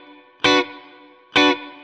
DD_StratChop_130-Bmin.wav